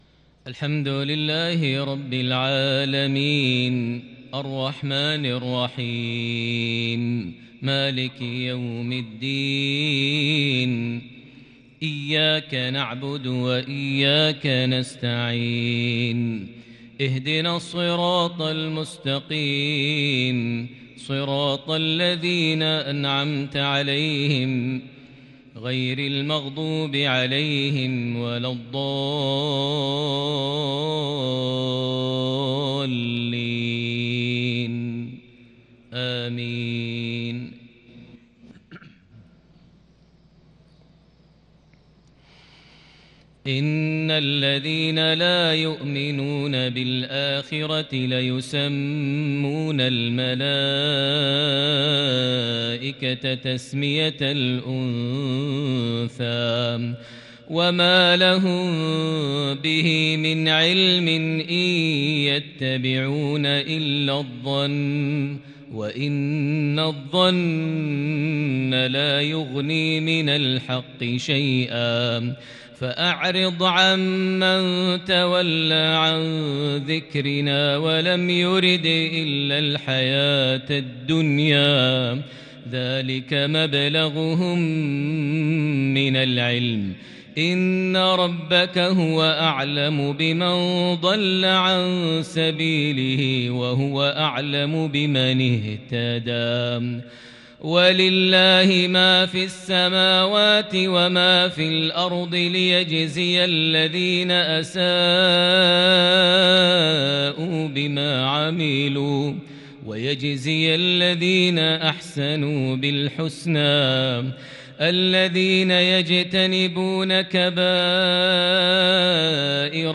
مغربية فذه من سورة النجم (27-55) | 27 ربيع الثاني 1442هـ > 1442 هـ > الفروض - تلاوات ماهر المعيقلي